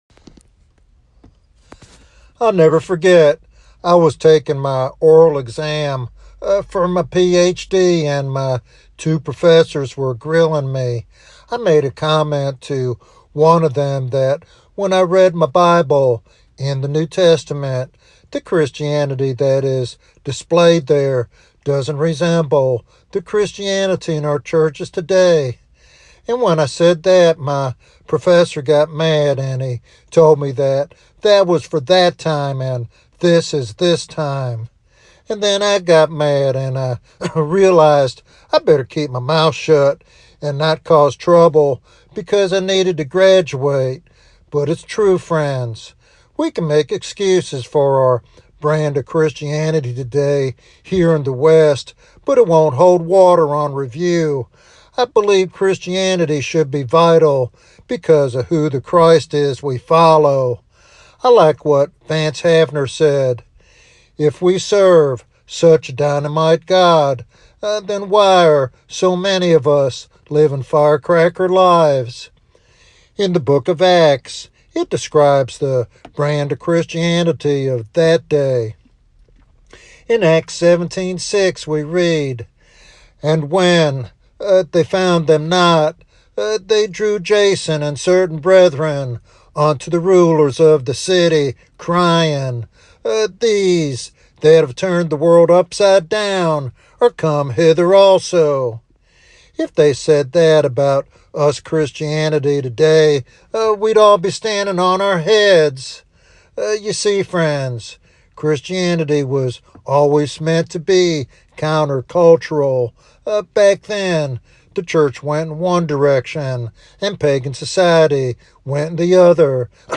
Is the sermon critical of modern churches?